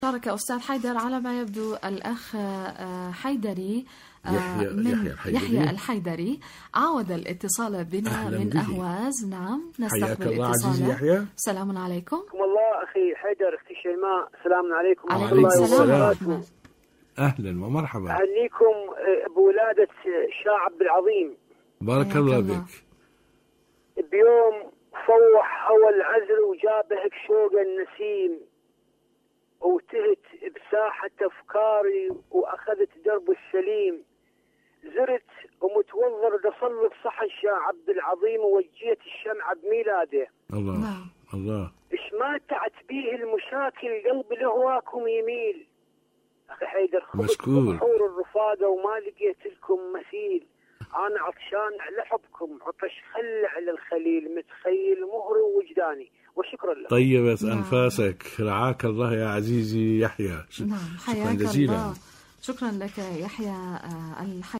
المنتدى الإذاعي /مشاركة هاتفية